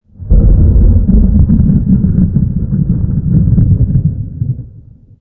thunder8.ogg